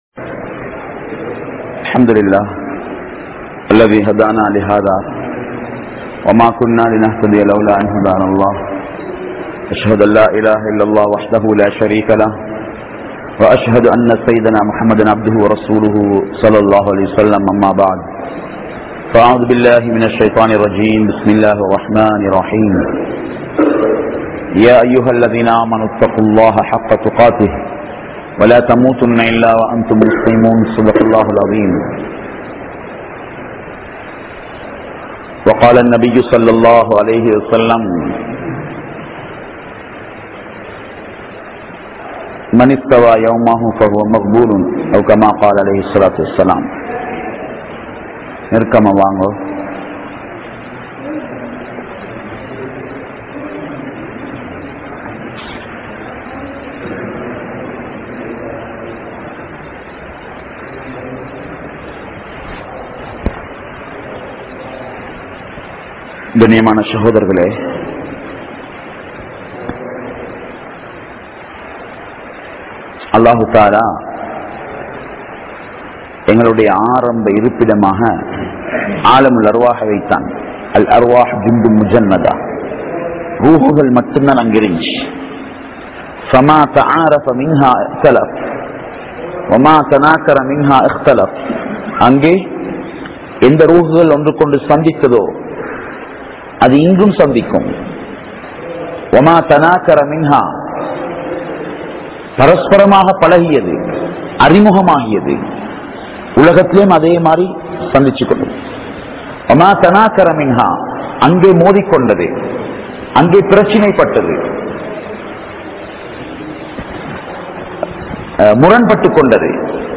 Islaathai Kolai Seium Indraya Muslimkal (இஸ்லாத்தை கொலை செய்யும் இன்றைய முஸ்லிம்கள்) | Audio Bayans | All Ceylon Muslim Youth Community | Addalaichenai